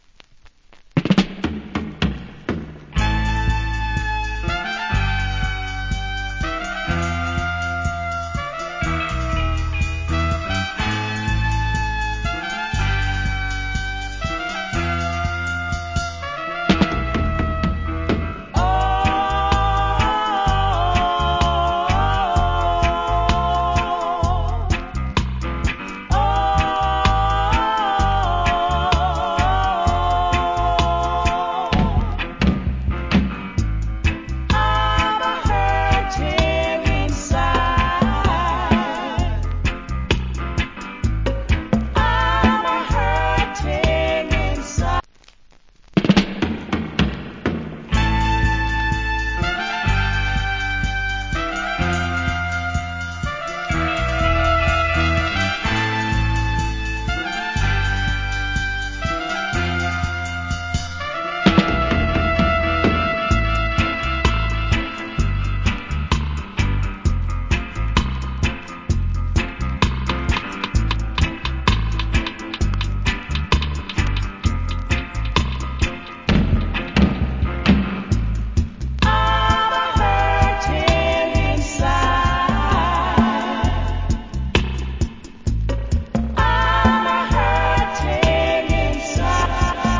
Killer Female Reggae Vocal.